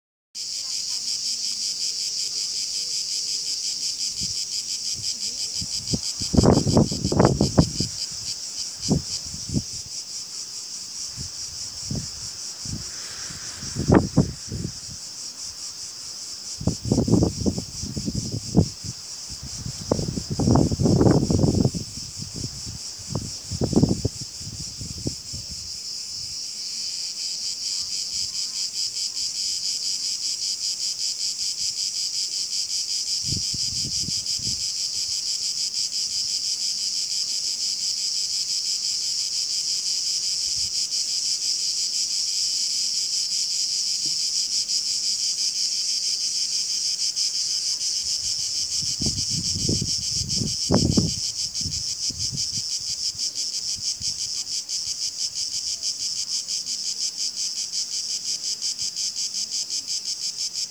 Infine, un ricordo da Serifos: un minuto di cicale e di vento.
cicale_serifos.m4a